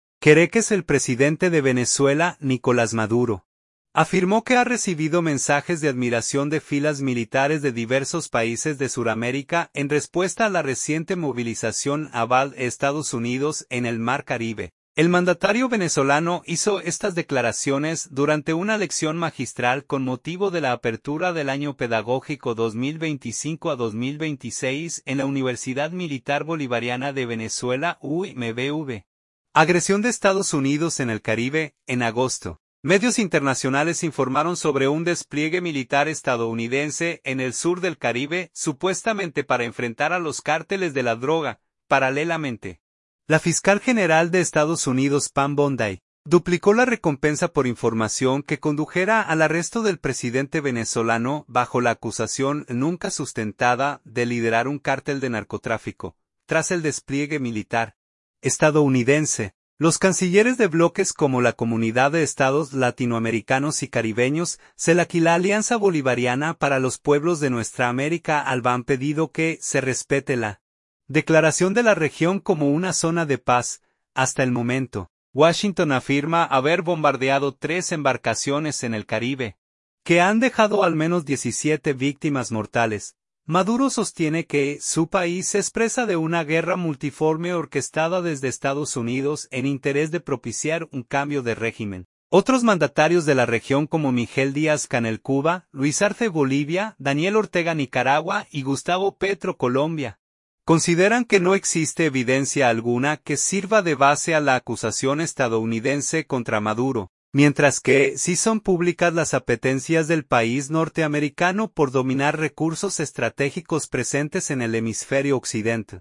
El mandatario venezolano hizo estas declaraciones durante una lección magistral con motivo de la apertura del año pedagógico 2025-2026 en la Universidad Militar Bolivariana de Venezuela (UMBV).